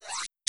UI_MenuOpen.wav